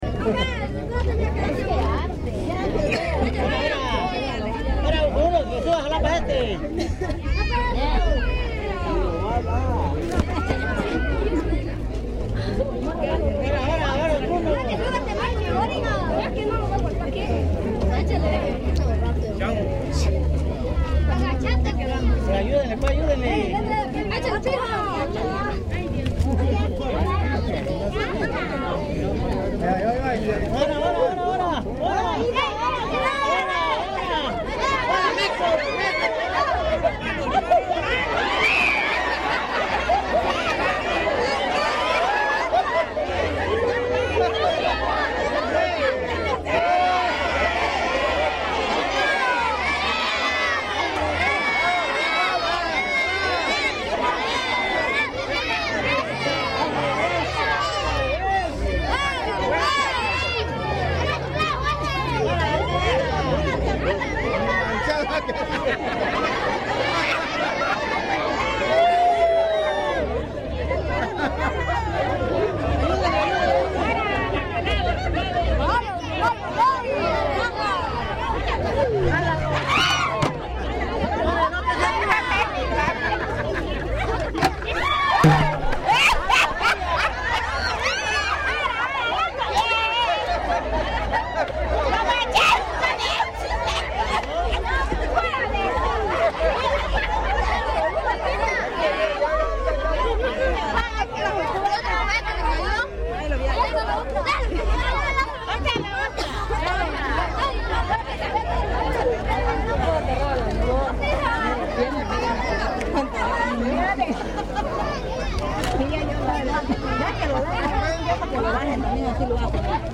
Durante la celebración de la Vírgen de las Mercedes en Suchiapa, se llevan a cabo dos juegos tradicionales de esa festividad, el palo encebado y corrida de listones.
El audio capta los momentos en que los niños intentan y alcanzan los regalos, espero que sea el disfrute de todos ustedes.
Equipo: Minidisc NetMD MD-N707, micrófono de construcción casera (más info)